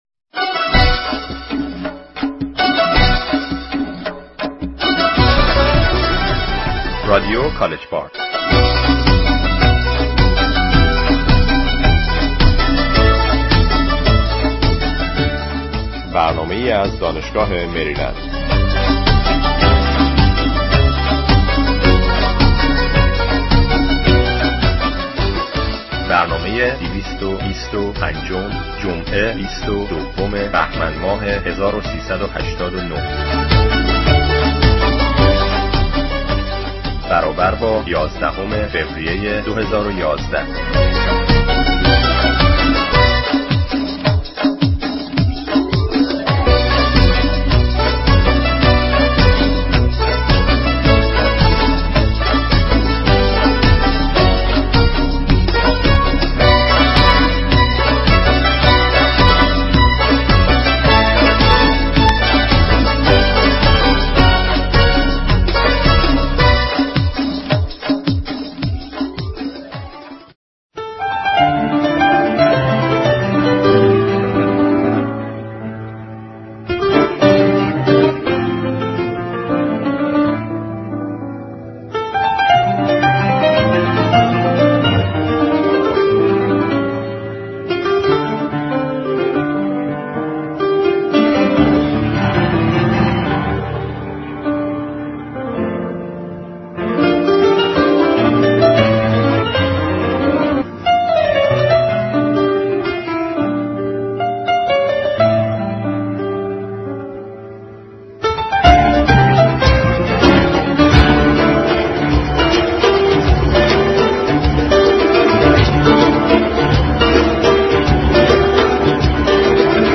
در آينده اگر يك كم بلند تر و محكمتر صحبت كنيند، خيلى عالى ميشود.
faghat sedaye guyande kheyli khaste be nazar miresyd!!
خستگی صدای گوینده از مزایای برنامه پر کردن در آخر شب هستش.